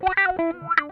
OUCH LICK 1.wav